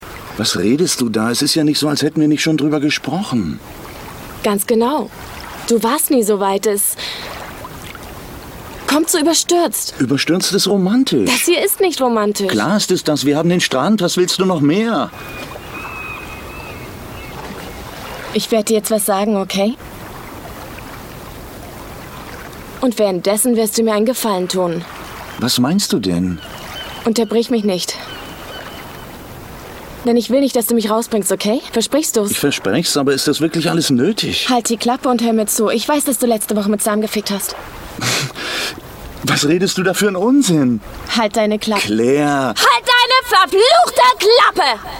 Sprecherin, Synchronsprecherin, Sängerin